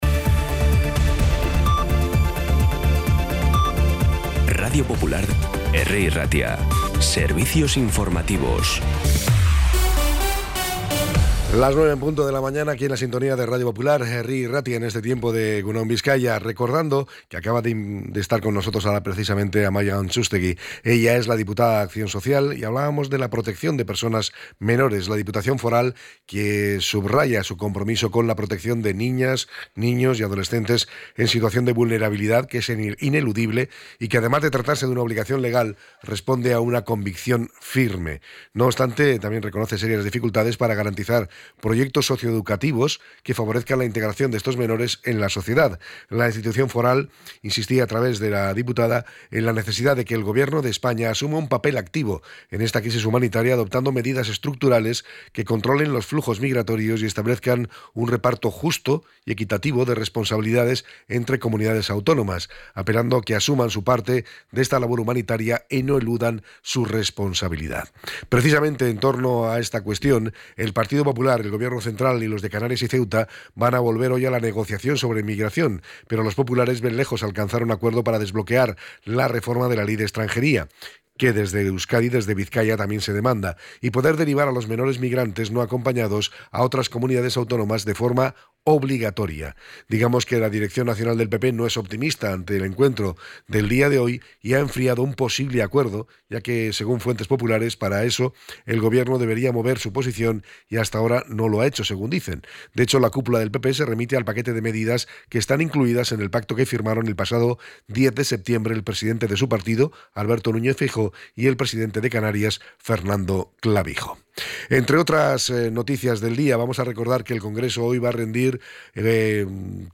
Las noticias de Bilbao y Bizkaia del 5 de diciembre a las 09